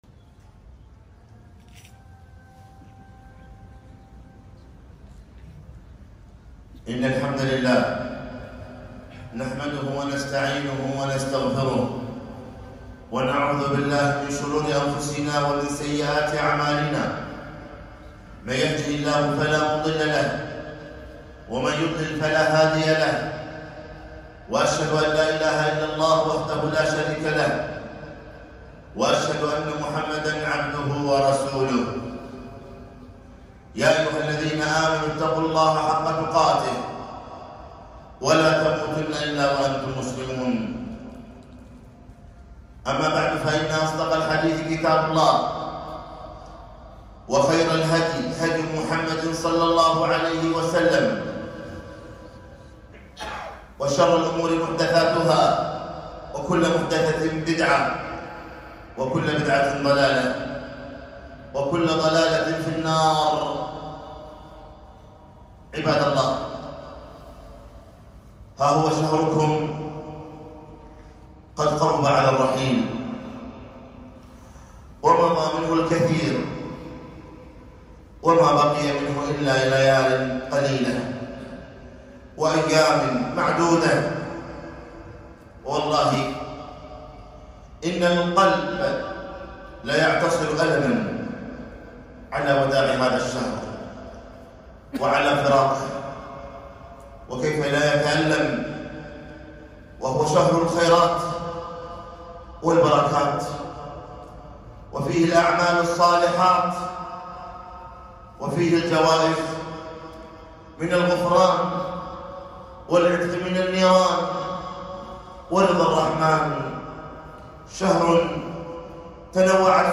خطبة - أحسن فيما بقي من شهر رمضان